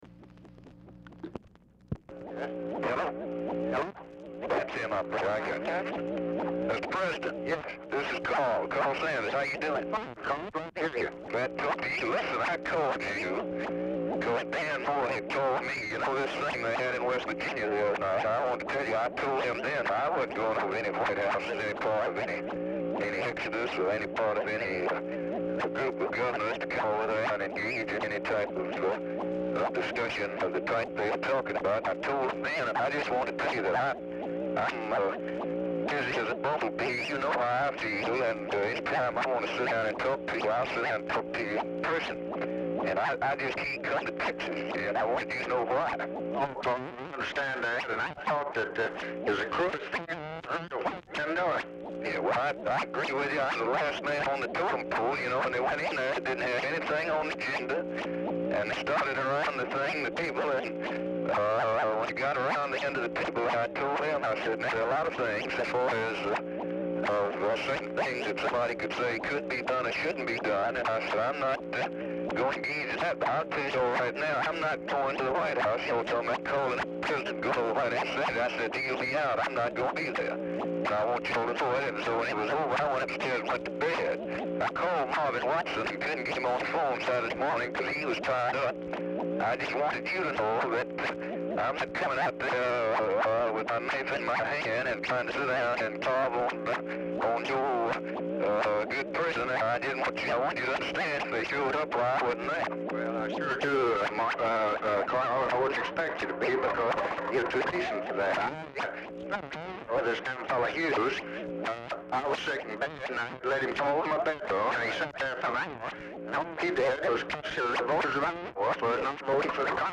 Telephone conversation # 11165, sound recording, LBJ and CARL SANDERS, 12/20/1966, 11:35AM | Discover LBJ
VERY POOR SOUND QUALITY; LBJ AND SANDERS ARE DIFFICULT TO UNDERSTAND DUE TO SOUND DISTORTION
Format Dictation belt
Specific Item Type Telephone conversation